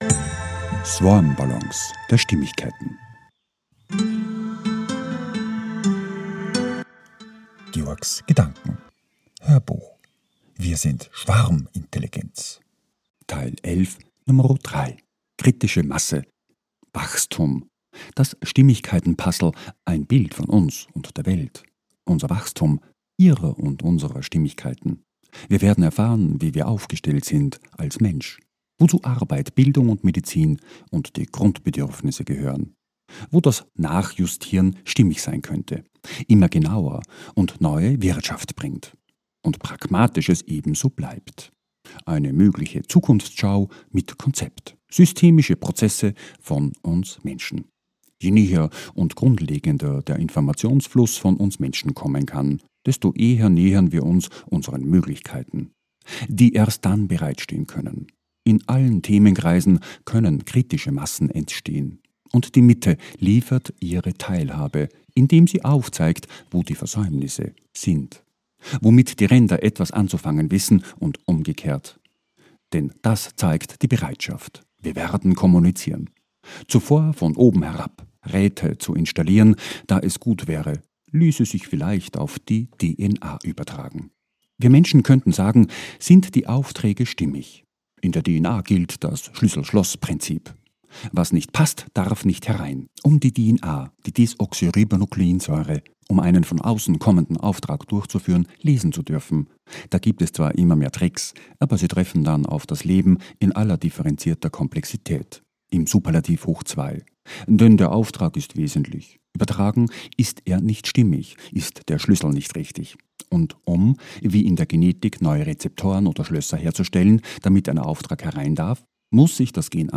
HÖRBUCH - 011.3 - WIR SIND SCHWARMINTELLIGENZ - Kritische Masse WACHSTUM